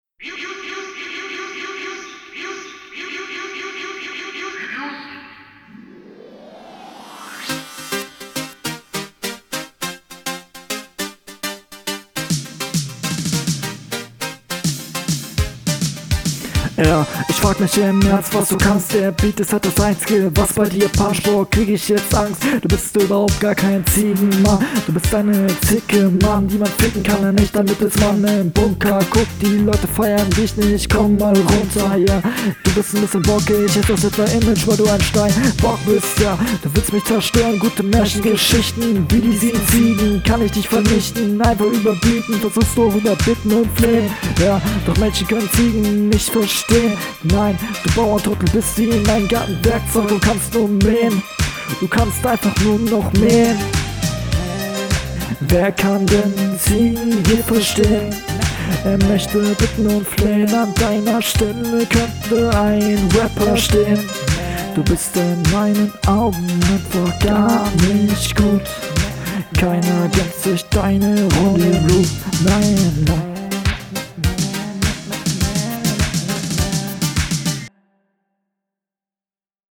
Der Stimmeinsatz ist leider wieder sehr unsicher, die Delivery fehlt halt auch erneut, wenn alles …
Flow: Flow ist wie in der Hr halt unsauber UND VORALLEM MIT DEM AUTOTUNE Text: …
Flow: Ist strukturierter als in der HR, geht klar, teilweise aber etwas holprig finde ich.